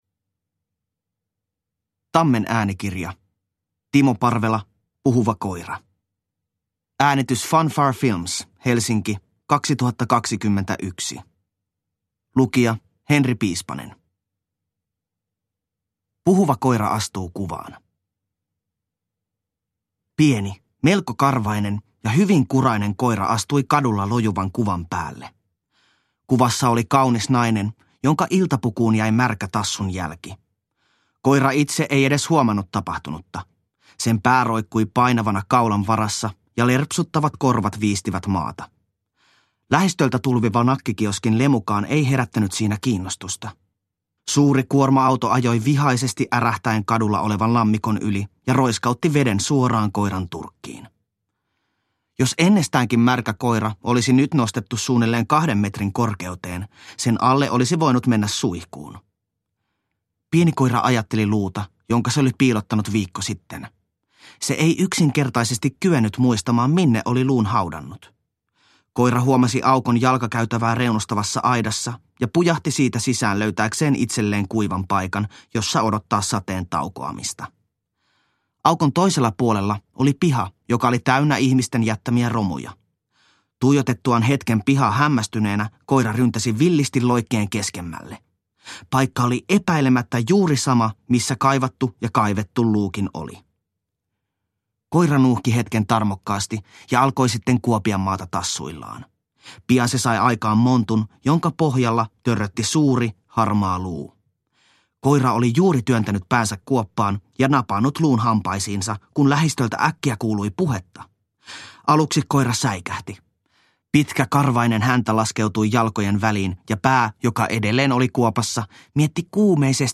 Puhuva koira – Ljudbok – Laddas ner